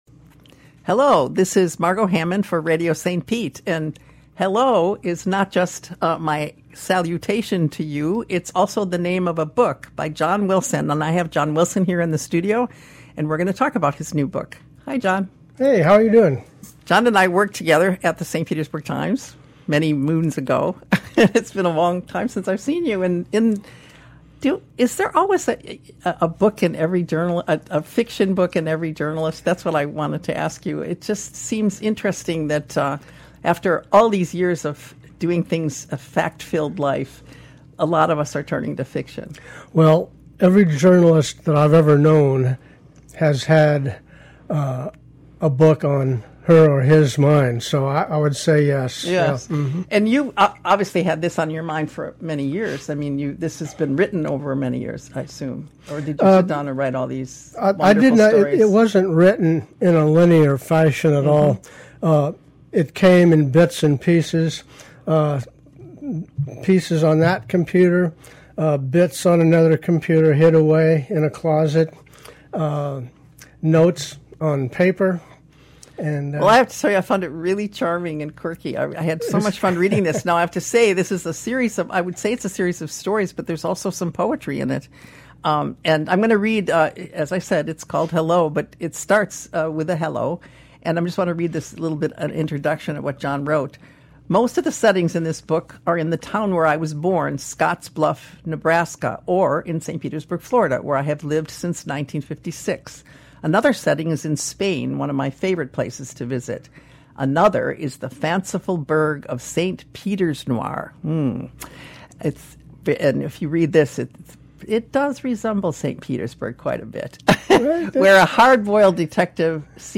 In this episode of Book Talk, host